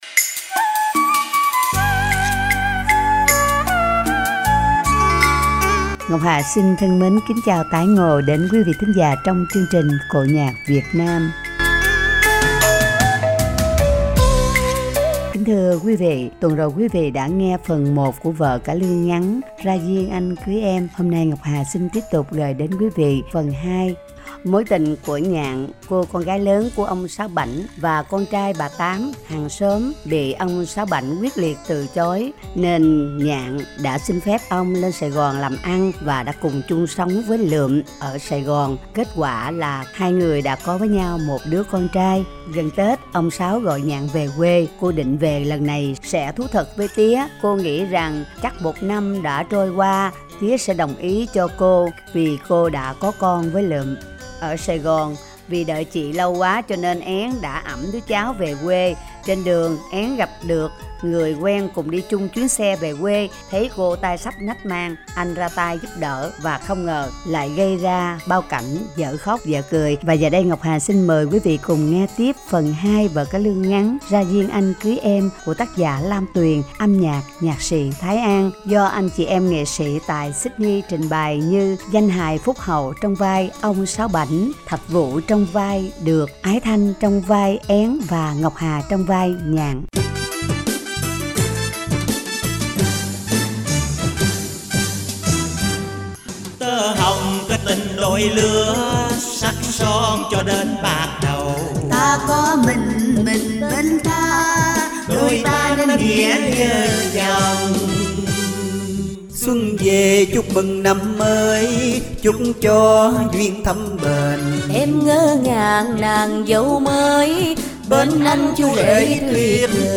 vở cải lương ngắn